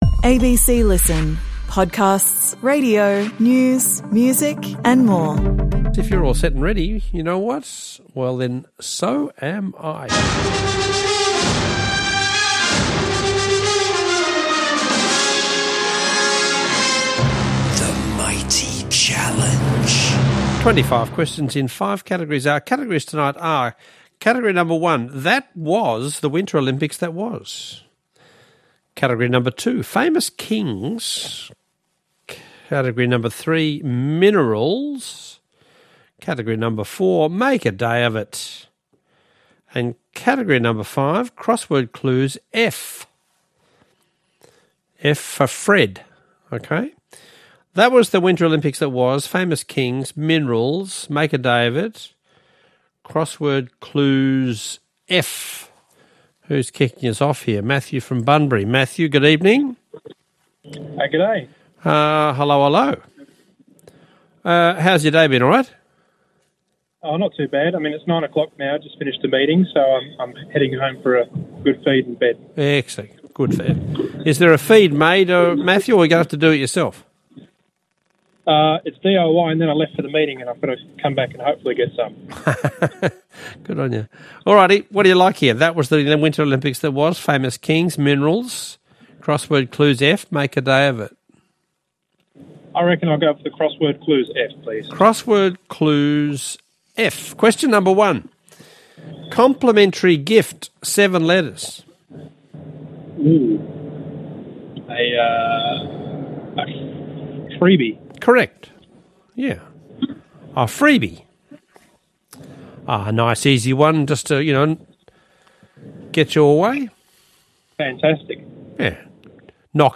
Are you into trivia? Calling all connoisseurs of the cryptic to the only quiz played live, all around Australia.